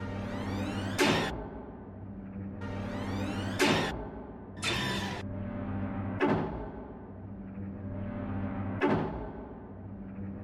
门 " 酒店房间门锁
描述：转动酒店房间门上的锁来锁住它。
标签： 点击 弗利 酒店 机械 汽车旅馆房间 解开
声道立体声